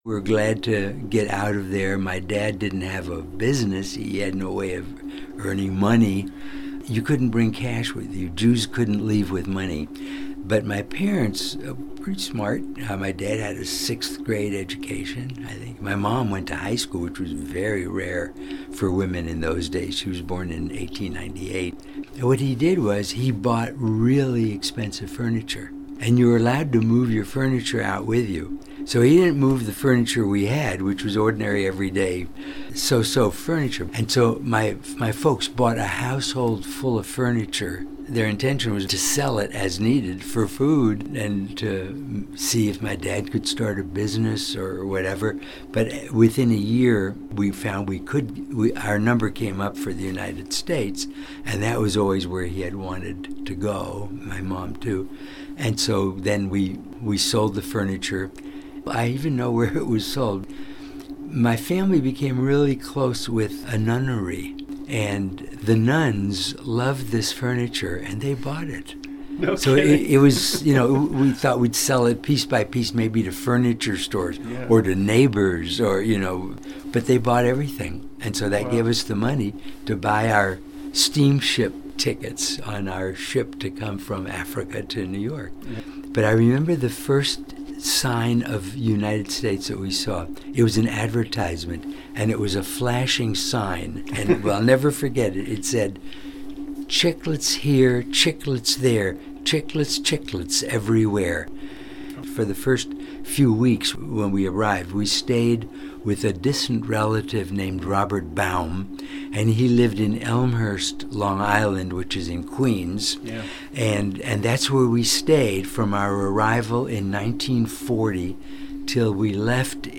I am pleased to present some tidbits from our conversation — all recalled by Dr. Rosenthal with his characteristic kindness and joviality.